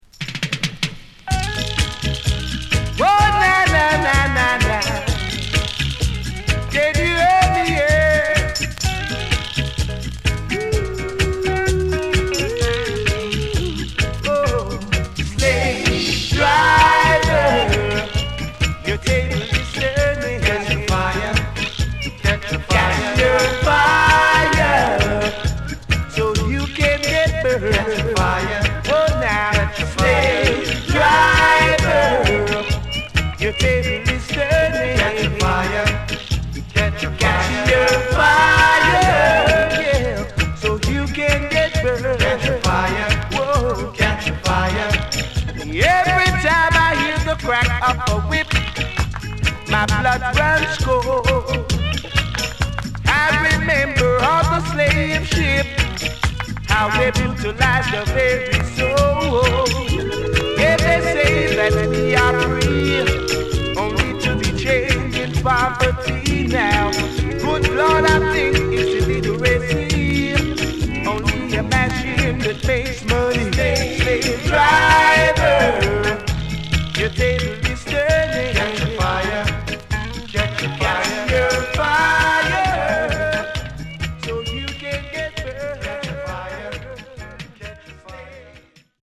HOME > LP [VINTAGE]  >  KILLER & DEEP
SIDE A:少しチリノイズ入りますが良好です。